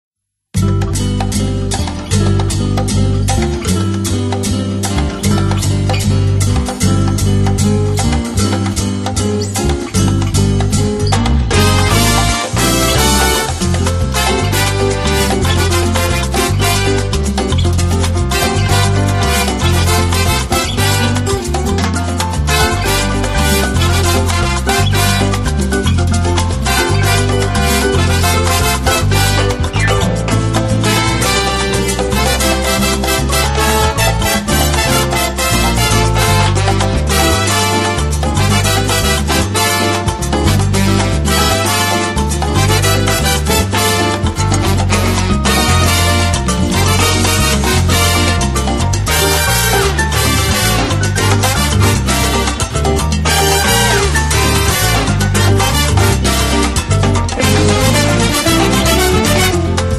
chitarre
Un collage di melodie fresche, di lontananze evocate.